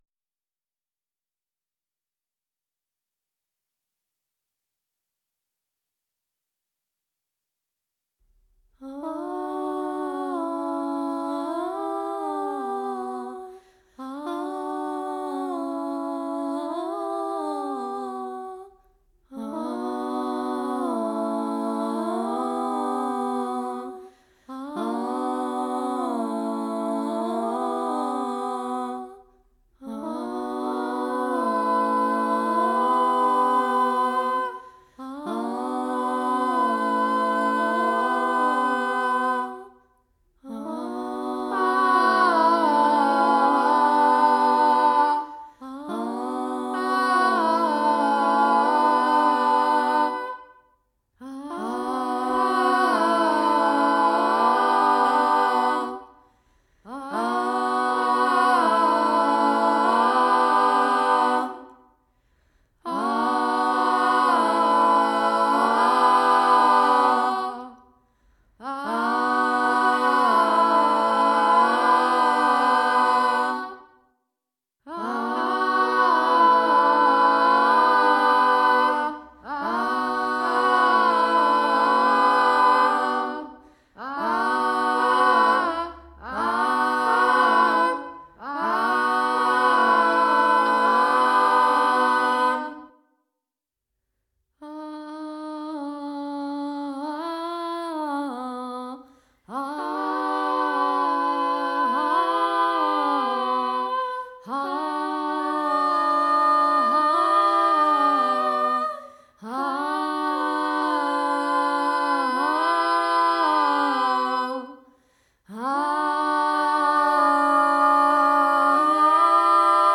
Eine Wohnung als abgeschlossenes System zeigt sich als Collage aus Objekten und Klängen.
An apartment as a closed system appears as a composition of objects and sounds.